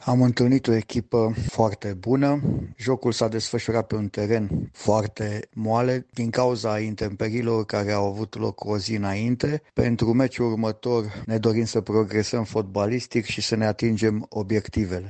a vorbit pentru Unirea FM despre jocul de la Reghin și cel care vine, în runda intermediară, pentru juniorii U19 din Sebeș.